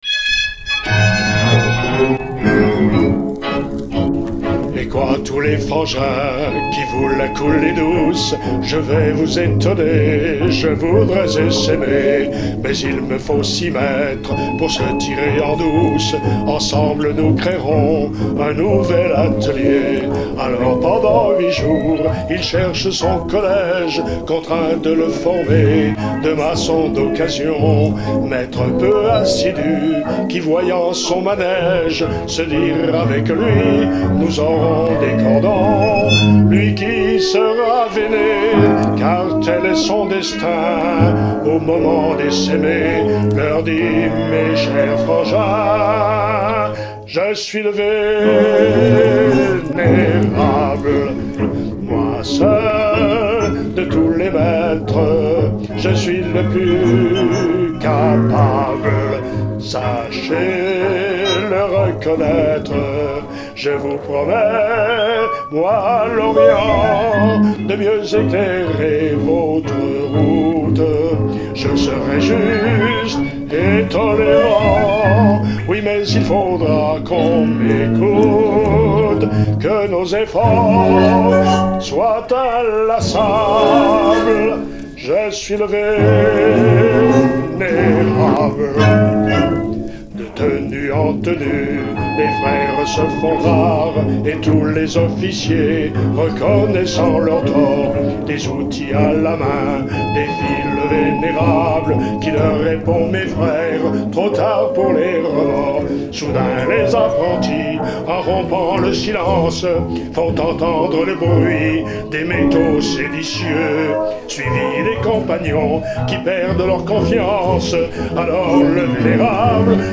Enregistrement local